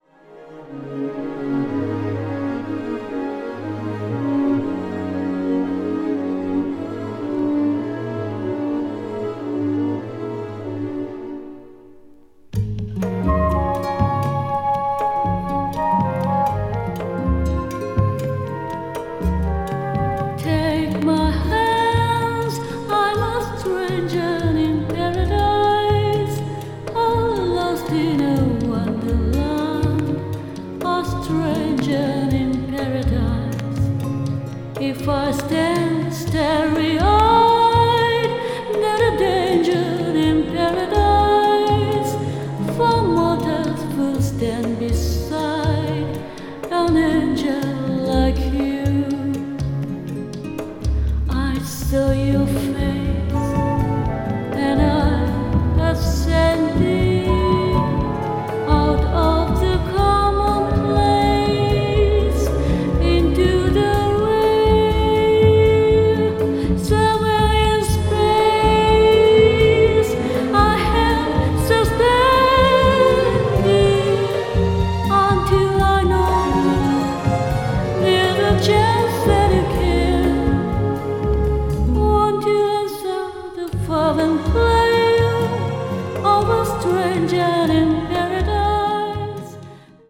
bossa jazz   contemporary jazz   jazz vocal   mood